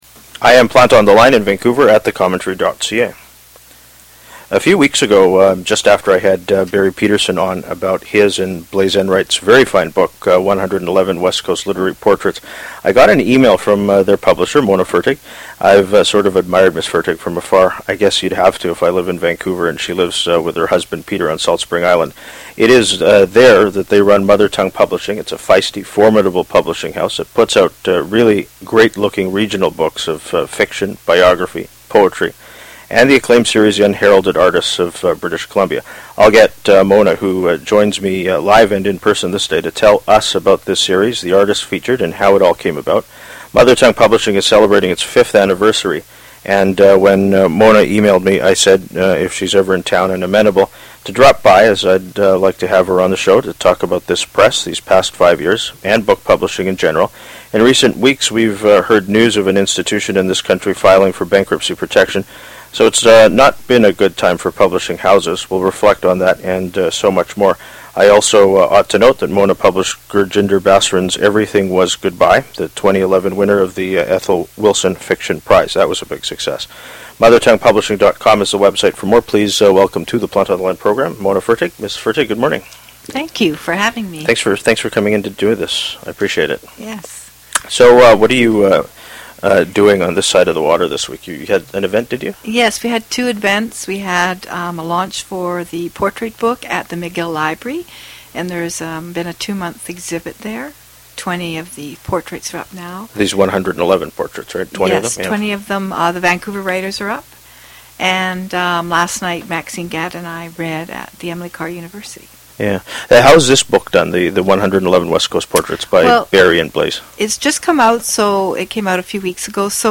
Text of introduction